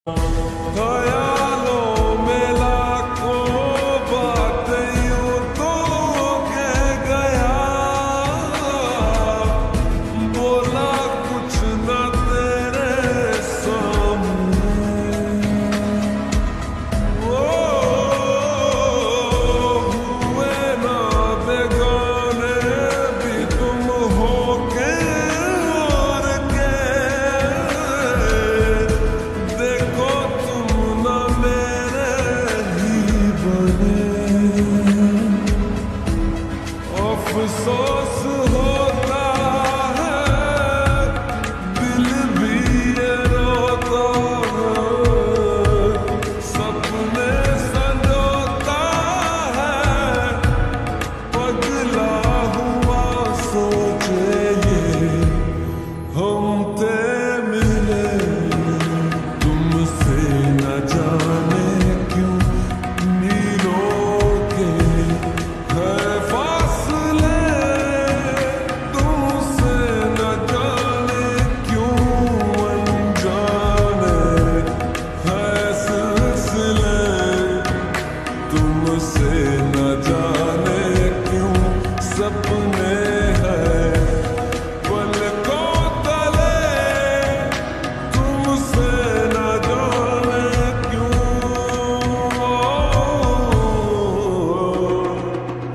46 dn departure from Rawalpindi sound effects free download